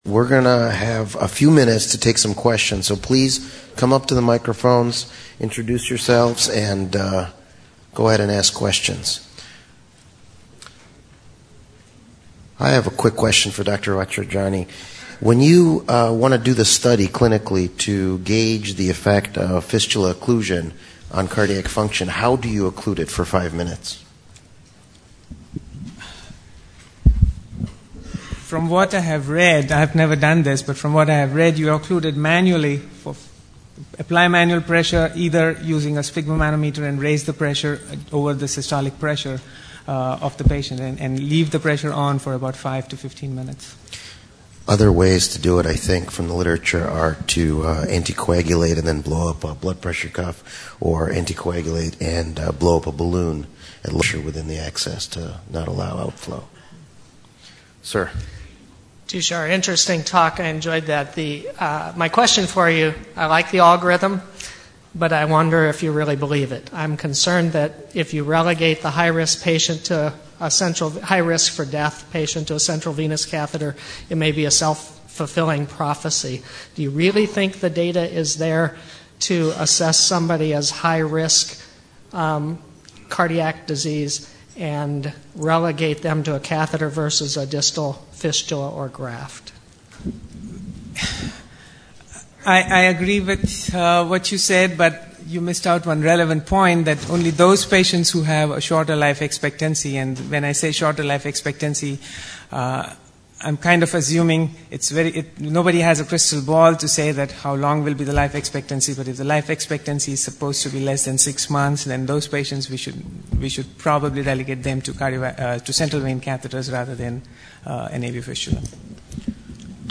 Discussions